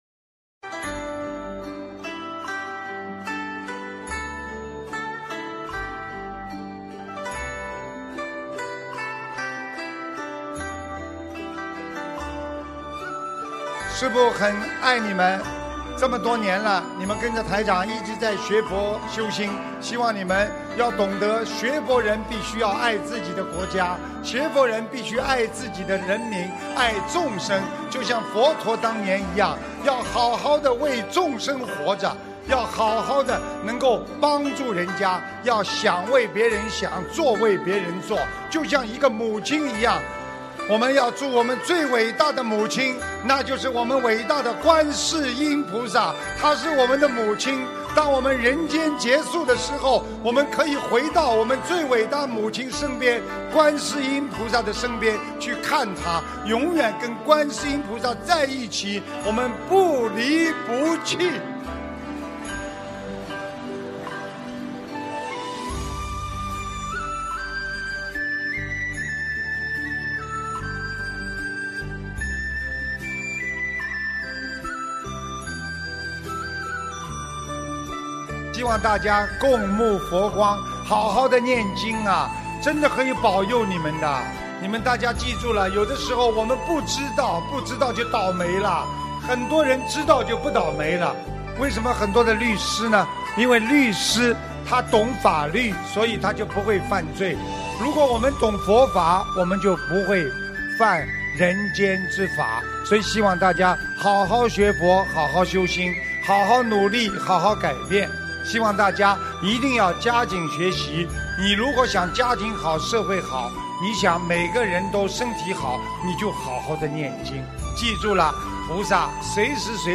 音频：意大利罗马共修组学佛素食分享会！2023年06月07日